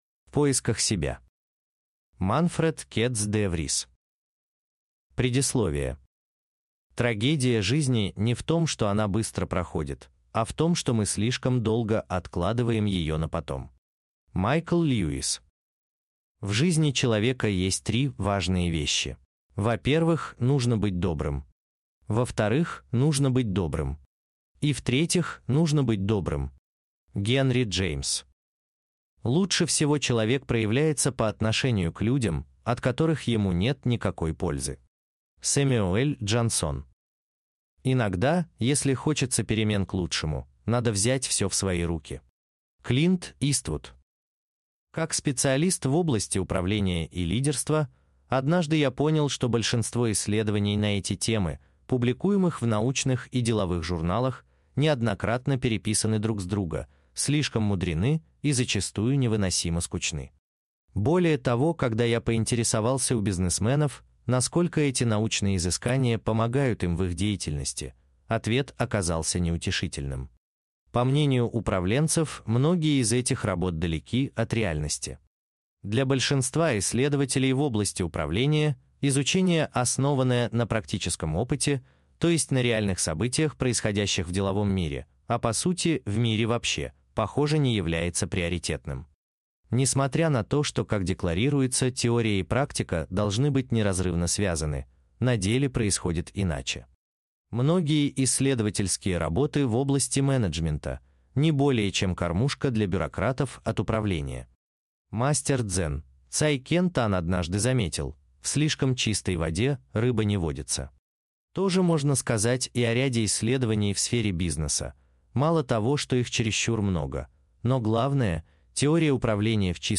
Аудиокнига Секс, деньги, счастье и смерть: В поисках себя | Библиотека аудиокниг